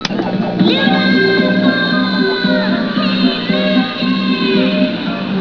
These are all origional sounds that we recorded ourselves, so please be so kind as to not steal them.